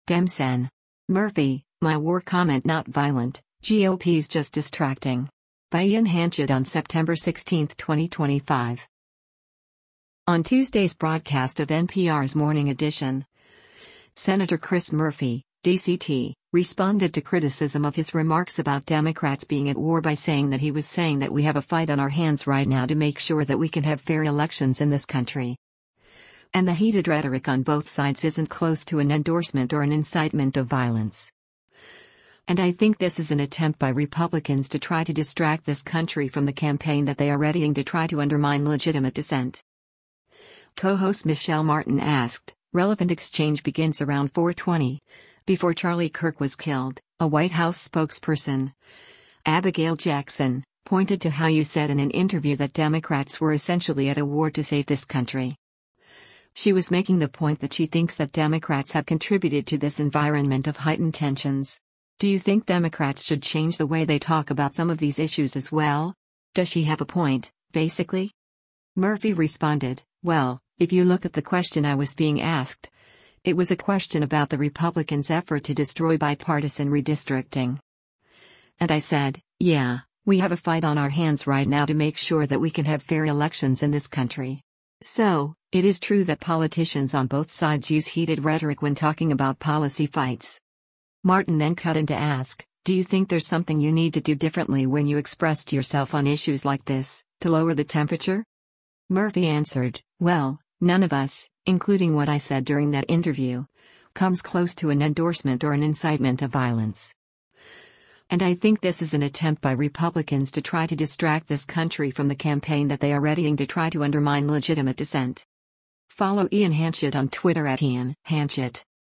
On Tuesday’s broadcast of NPR’s “Morning Edition,” Sen. Chris Murphy (D-CT) responded to criticism of his remarks about Democrats being at “war” by saying that he was saying that “we have a fight on our hands right now to make sure that we can have fair elections in this country.”